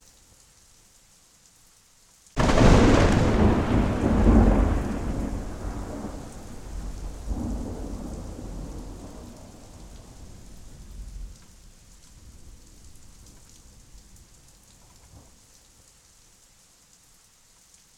lightning strike
explosion lightning thunder sound effect free sound royalty free Nature